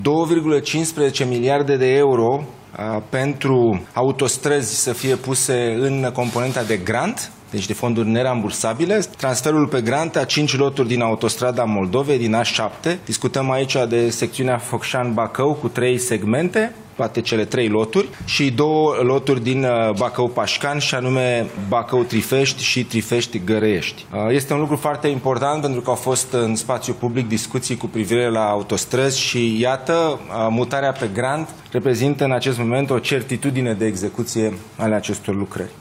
Ministrul Investițiilor și Proiectelor Europene, Dragoș Pîslaru, a precizat că mutarea pe grant oferă o certitudine în ceea ce privește execuția lucrărilor.